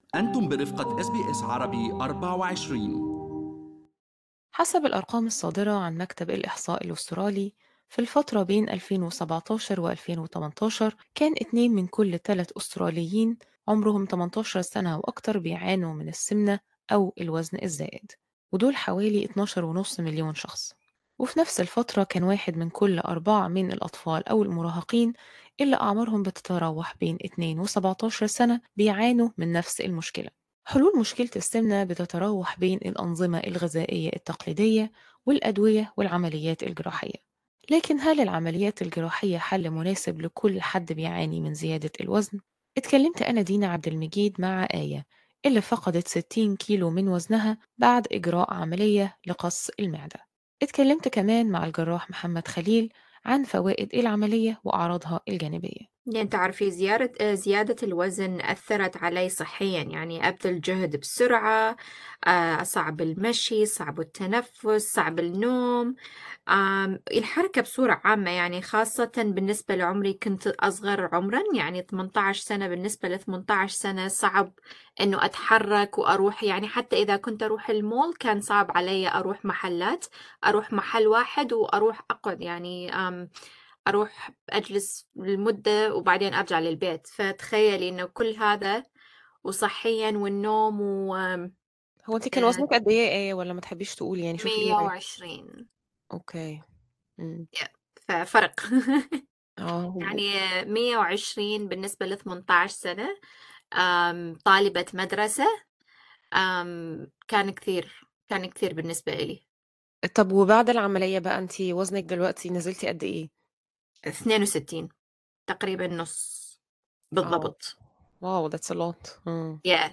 gastric-sleeve-surgeries-interview.mp3